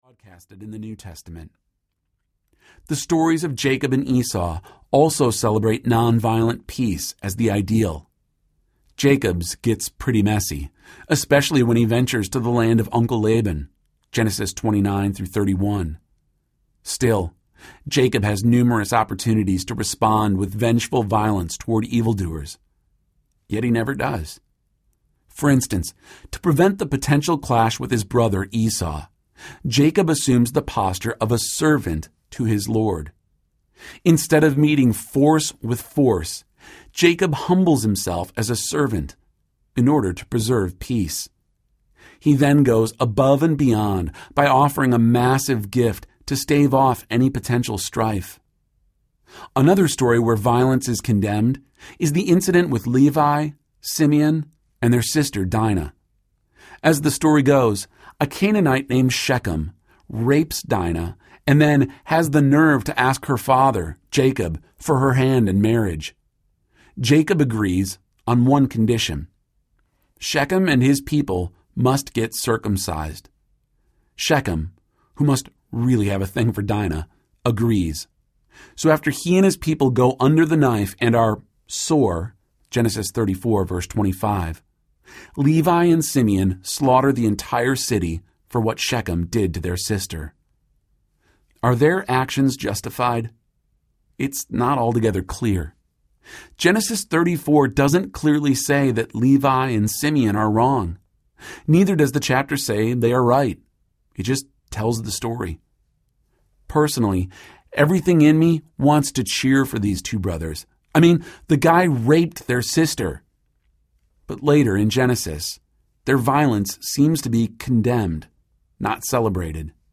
Fight Audiobook
8.65 Hrs. – Unabridged